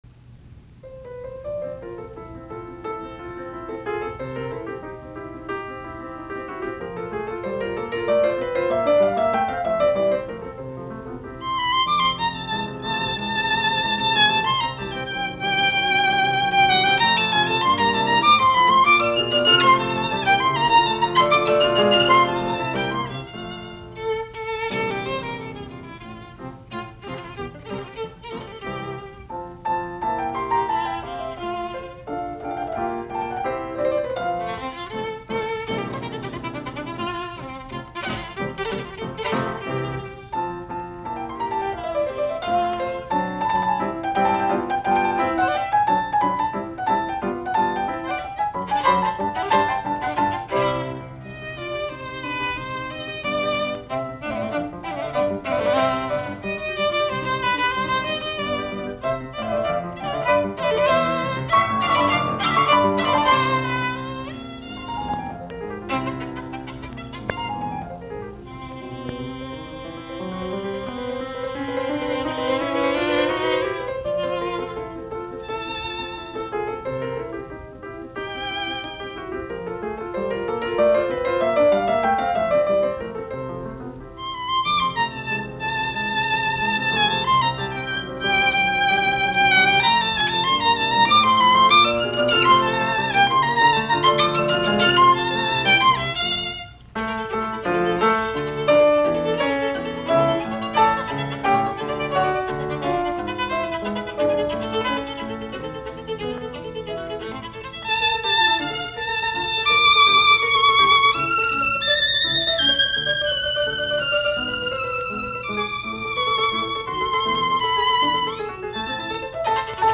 Sonaatit, viulu, piano, op24, F-duuri